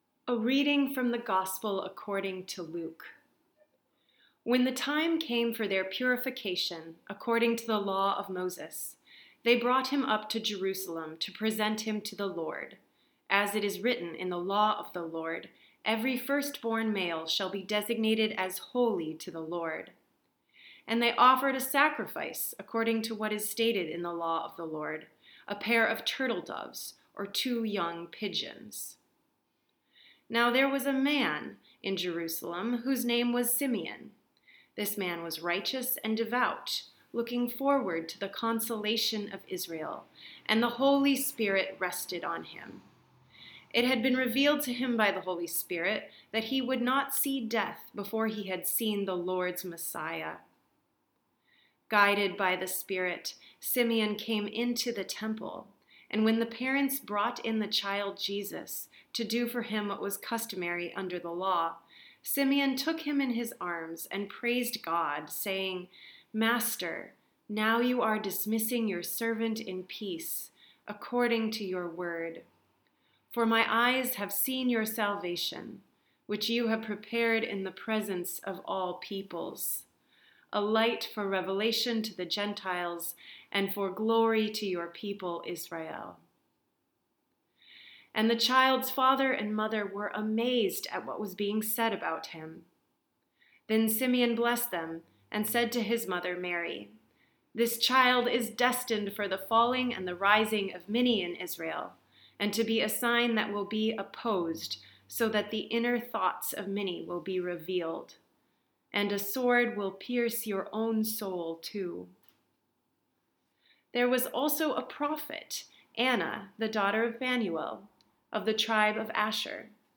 Feb 3, 2019 Sermon
Here’s my sermon for Candlemas, the fourth Sunday after Epiphany: